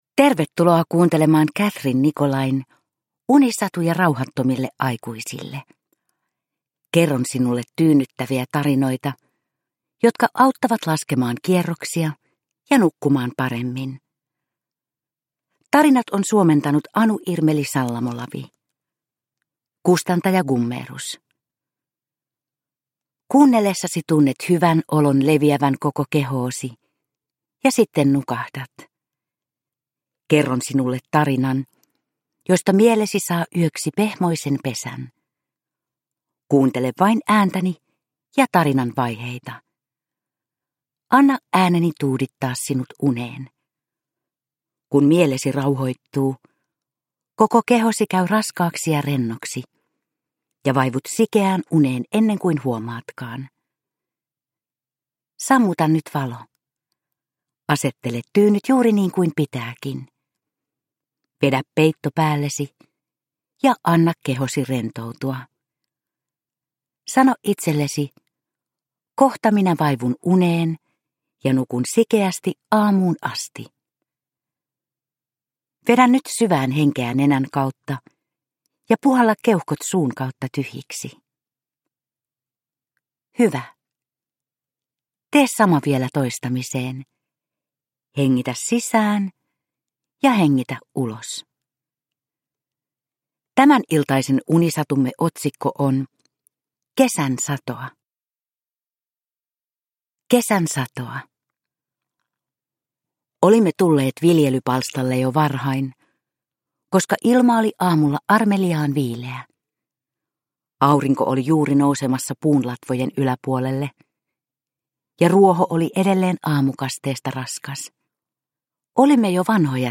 Unisatuja rauhattomille aikuisille 46 - Kesän satoa – Ljudbok – Laddas ner